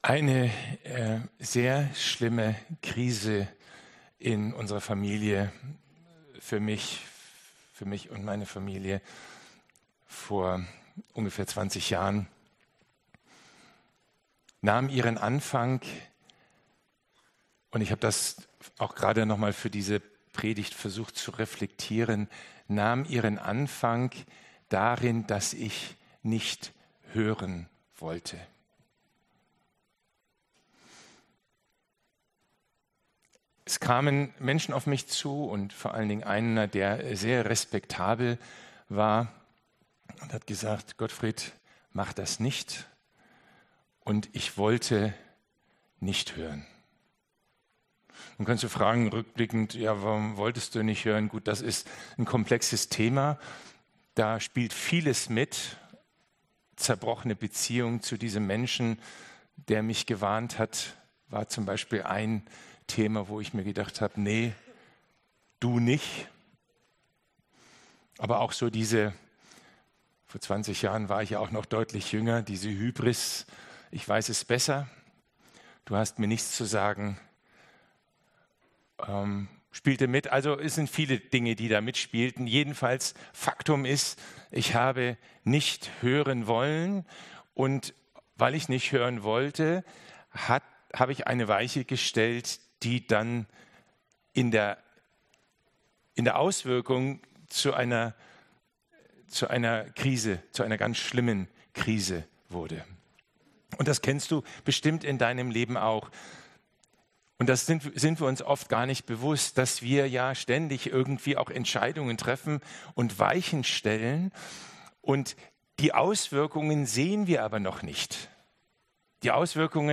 Predigt-Zusammenfassung